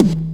just SNARES 3
snarefxldk02.wav